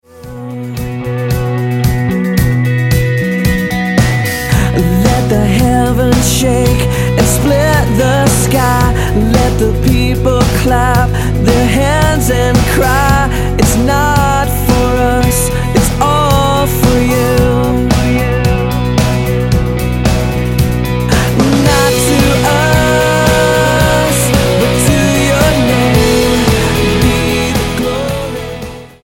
STYLE: Rock
surging bursts of rock guitar